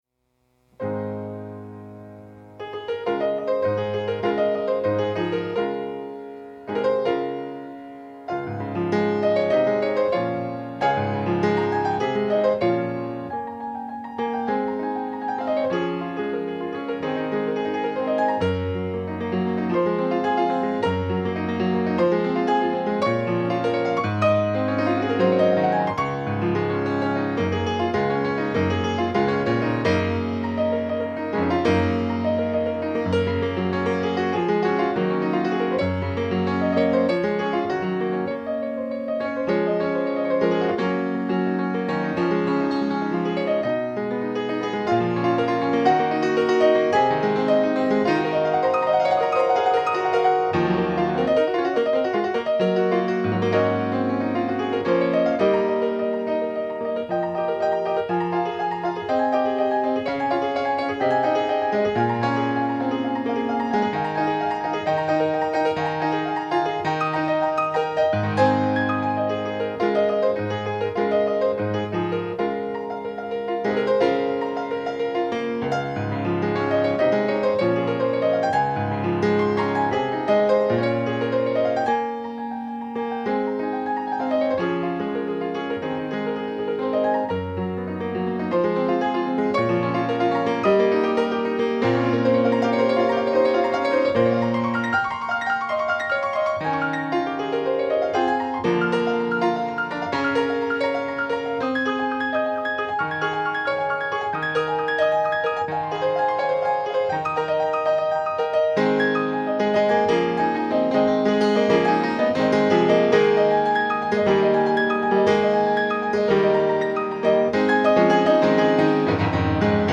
Klavierstücke
gespielt auf einem Feurich 197